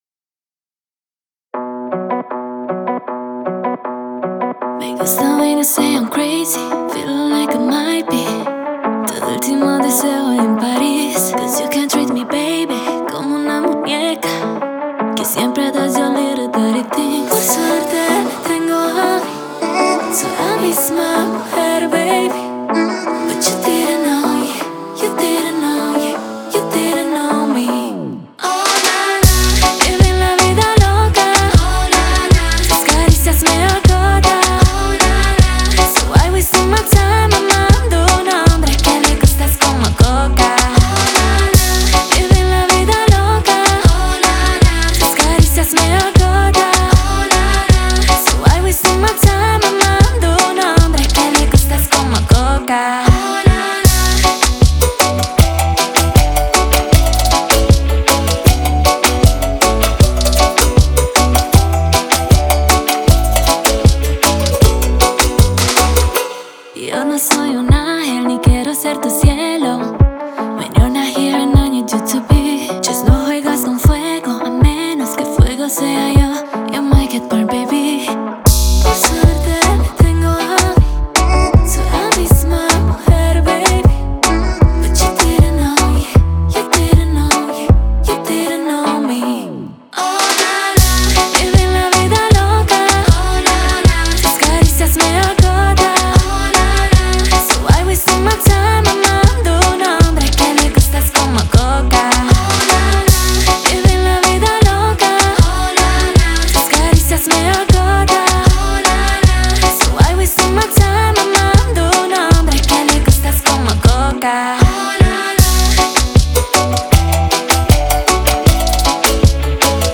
зажигательная поп-песня румынской певицы
запоминающимся мелодичным хором и ритмичным битом